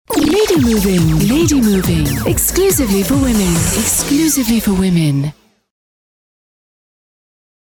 Soft, clear, voice.
Sprecherin französisch. Warme, klare Stimme. Spezialisiert auf Telefonansagen.
French female voice over talent.